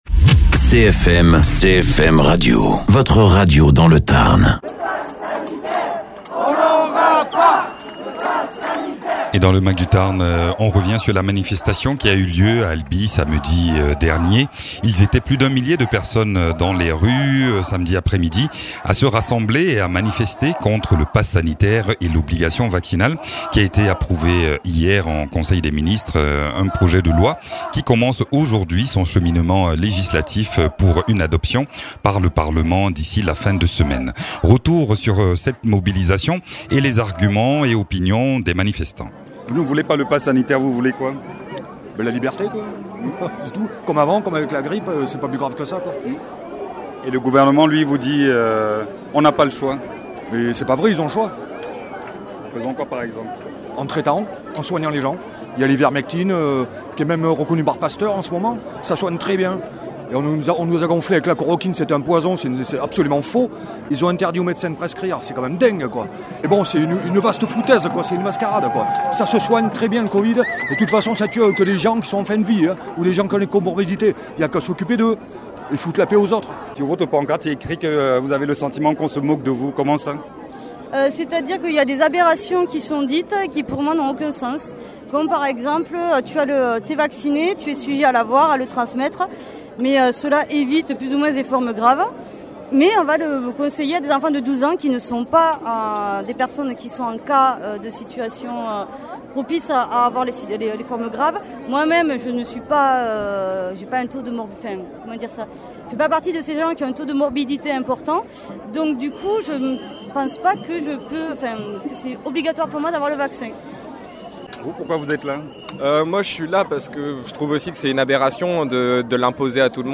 Contre le pass sanitaire reportage à Albi
Interviews